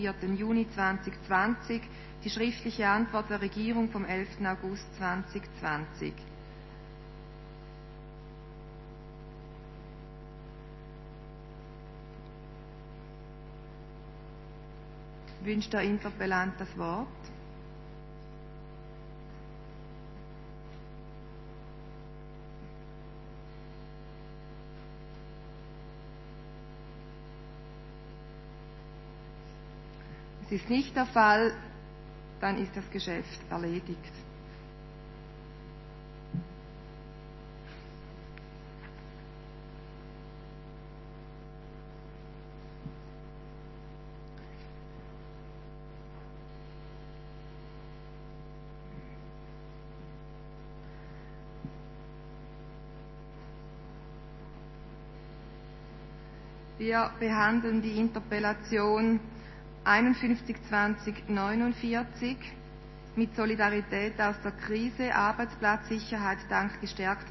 15.9.2020Wortmeldung
Session des Kantonsrates vom 14. bis 17. September 2020